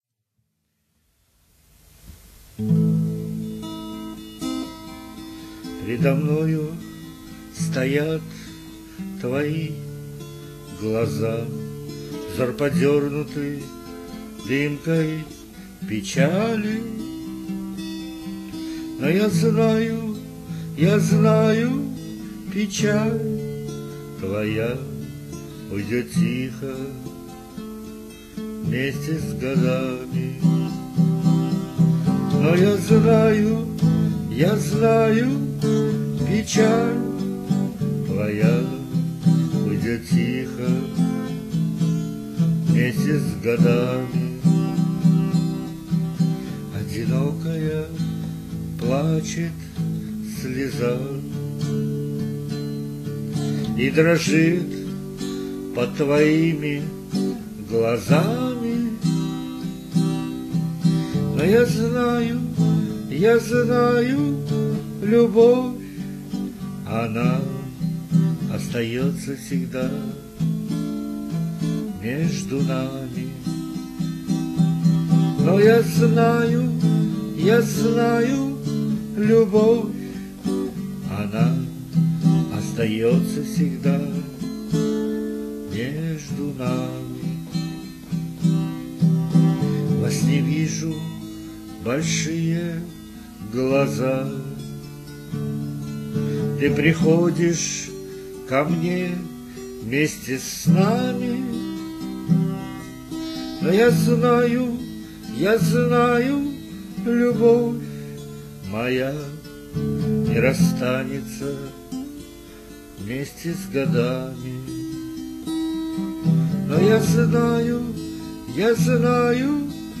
Музыкальный хостинг: /Авторская песня